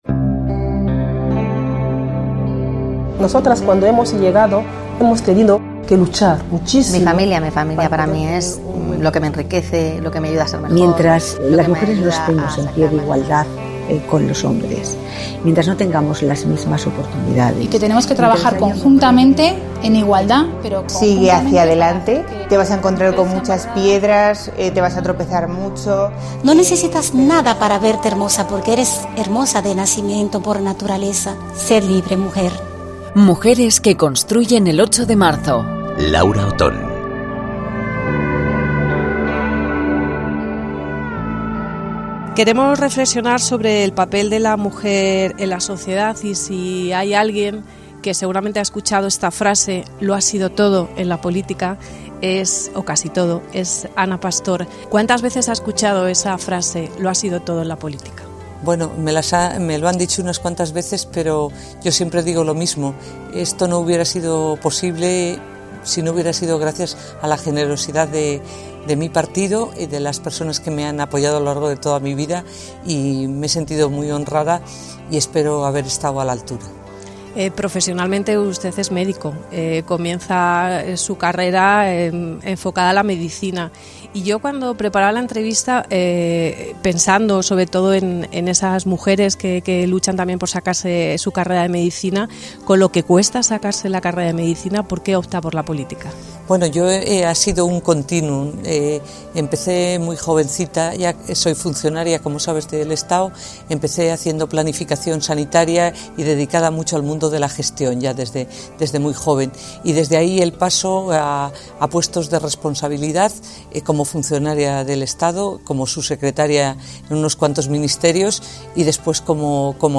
Ana Pastor Julián nos recibe en su despacho del Congreso de los Diputados el mismo día que se han disuelto Las Cortes.